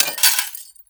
GLASS_Window_Break_10_mono.wav